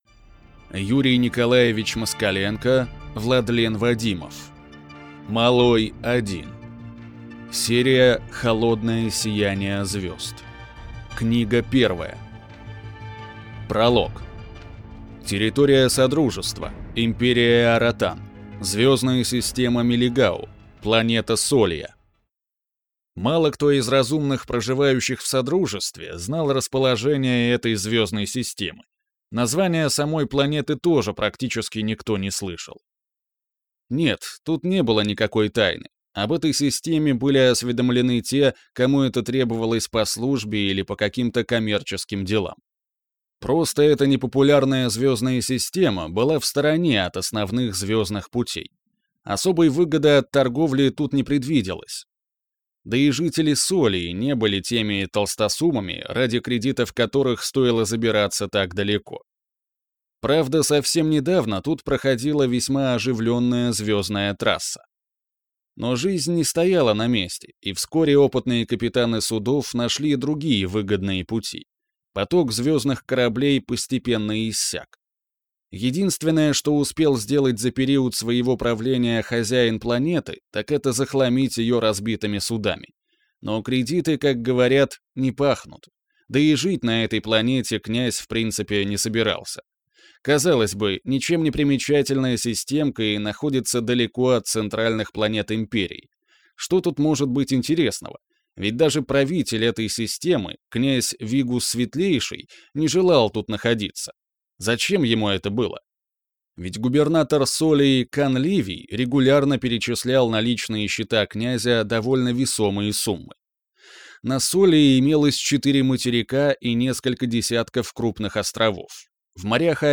Аудиокнига Малой 1 | Библиотека аудиокниг
Прослушать и бесплатно скачать фрагмент аудиокниги